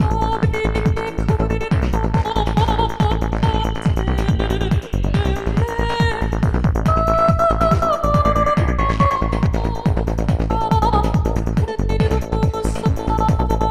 I’ve added a bit of reverb on the vocal for some extra space.